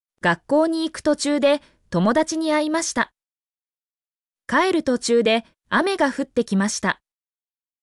mp3-output-ttsfreedotcom-16_bOoAEqmC.mp3